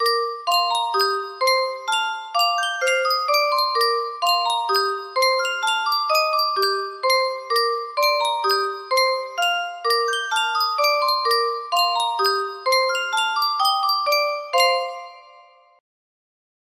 Yunsheng Music Box - Unknown Tune 1488 music box melody
Full range 60